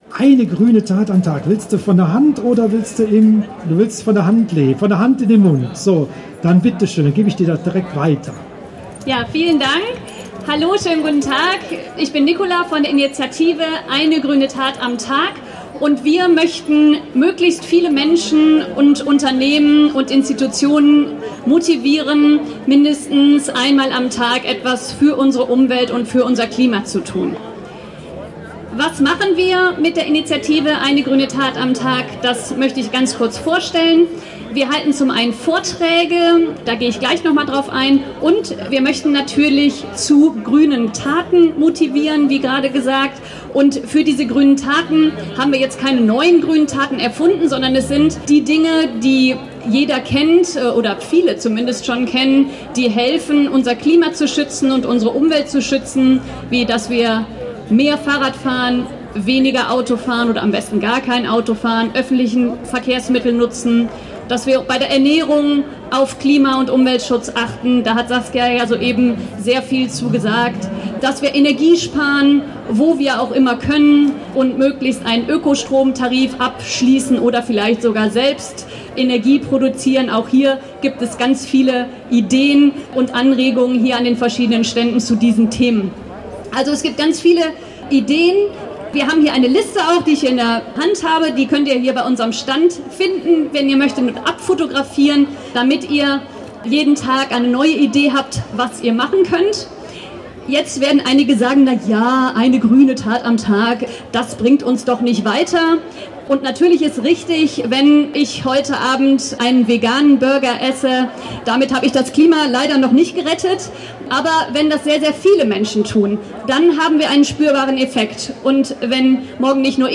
„Kölle for Future Klima-Infotag“ (Audio 10/16)